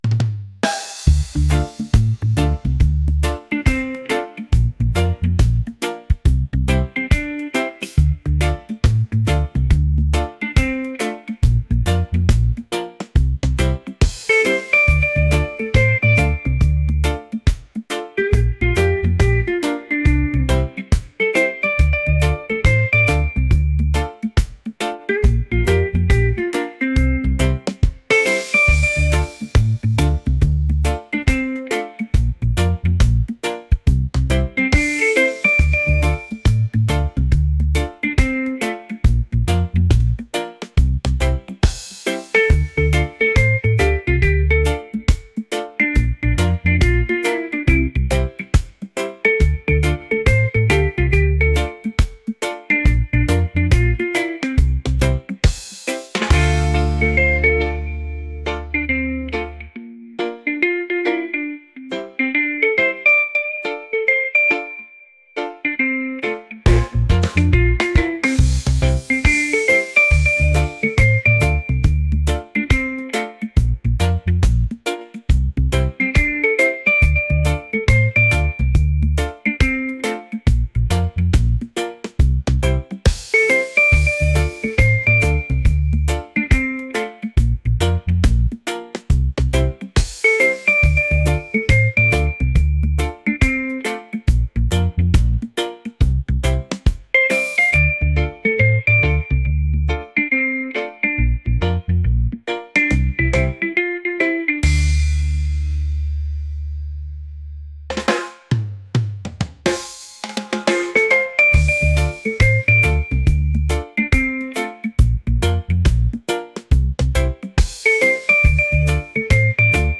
groovy | reggae